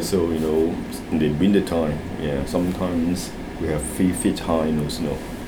S1 = Taiwanese female S2 = Hong Kong male Context: S2 is talking about the unexpected cold weather that sometimes occurs in northern Texas. S2 : ... so you know s- in the winter time yeah sometimes we have three feet high of snow Intended Words : three Heard as : few Discussion : There are two pronunciation issues with this word which cause it to be misunderstood. First, the initial voiceless TH sound is pronounced as [f] (as is common in Hong Kong). Second, there is no [r].